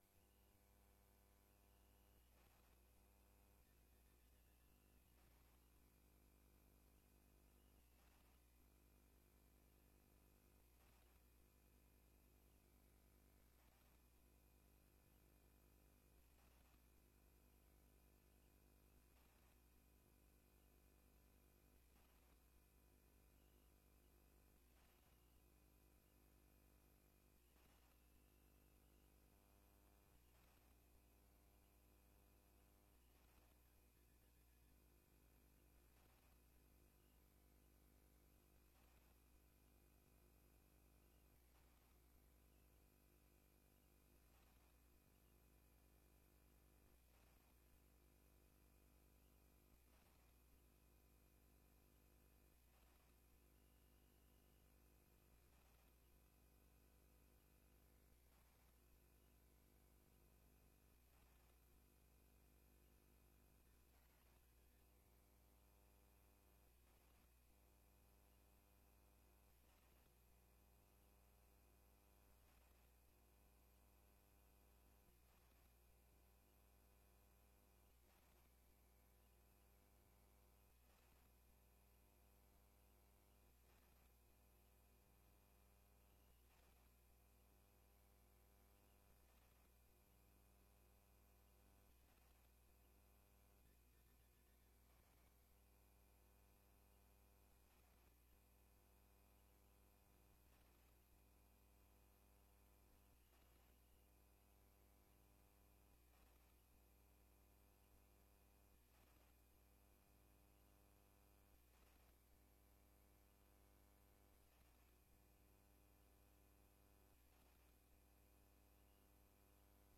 Hierbij nodig ik u uit voor de vergadering van de gemeenteraad op 12 september 2024, aanvang 19:00 uur in De Beeck te Bergen.
Raadzaal